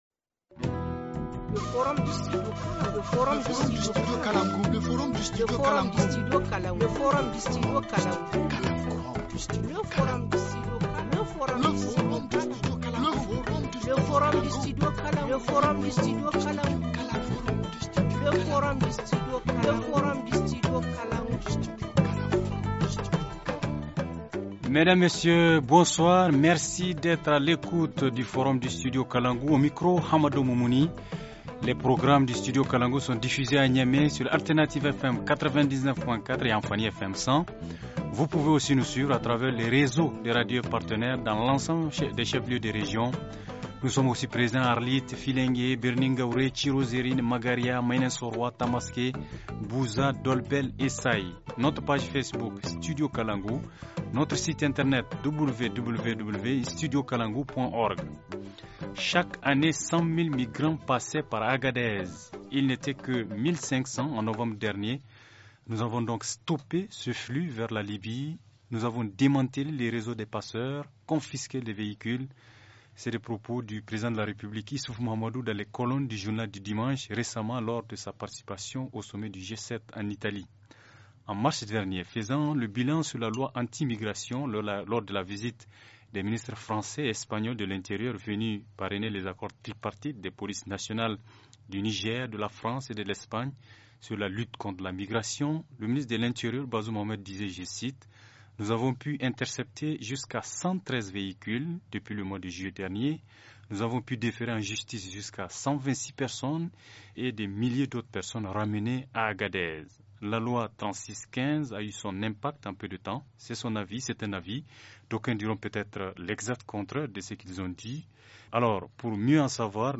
(en ligne depuis Agadez)
Forum en Français